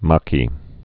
(mäkē)